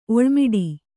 ♪ oḷmiḍi